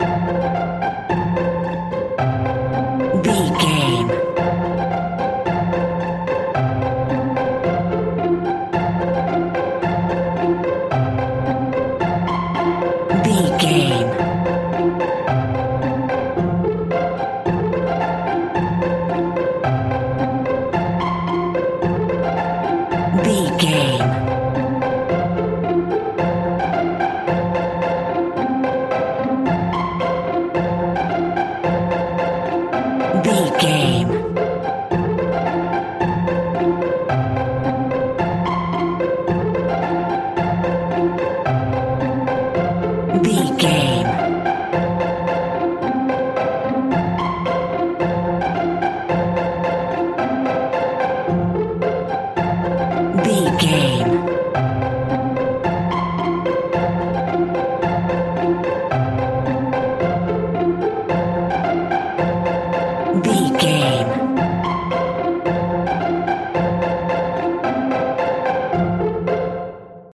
Ionian/Major
nursery rhymes
kids music